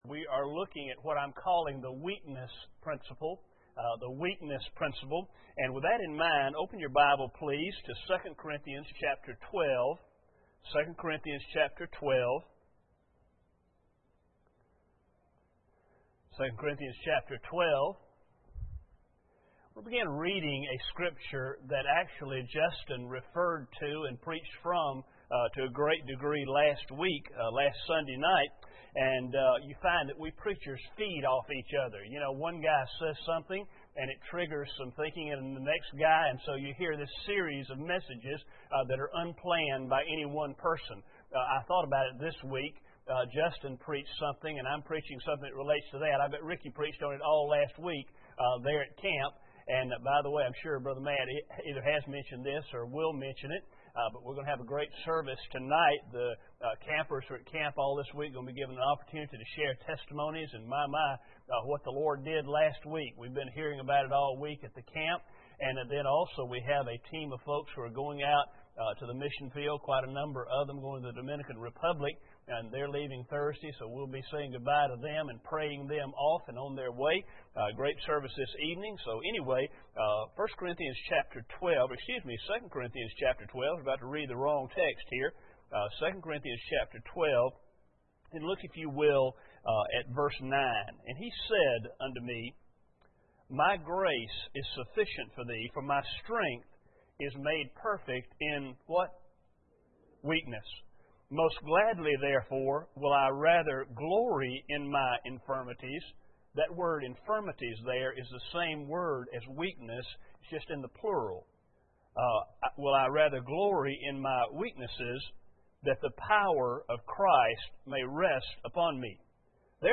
2 Corinthians 12:9 Service Type: Sunday Morning Bible Text